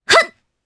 Epis-Vox_Jump_jp.wav